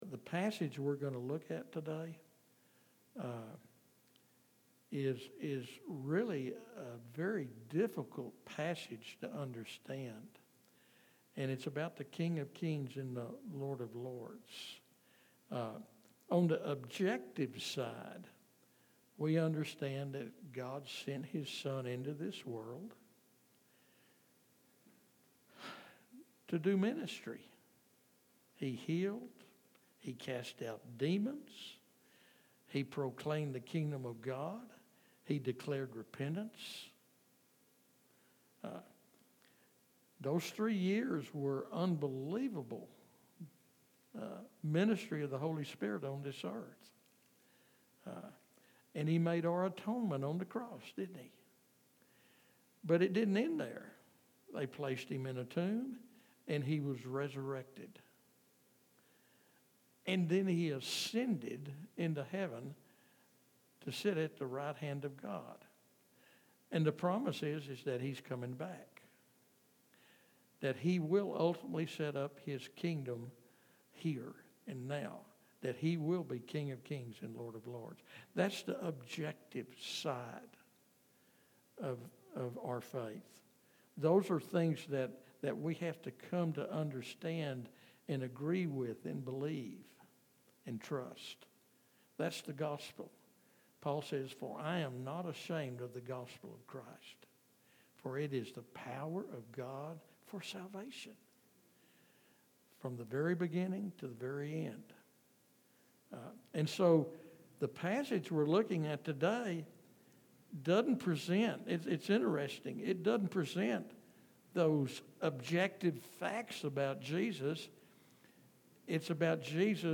Can Jesus be Savior without being Lord? This sermon challenges popular gospel presentations and calls us to examine whether Christ truly has first place in our lives.